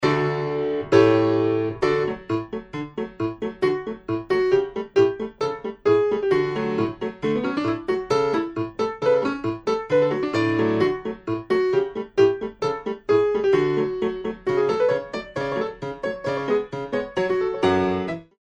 52 solo piano tracks for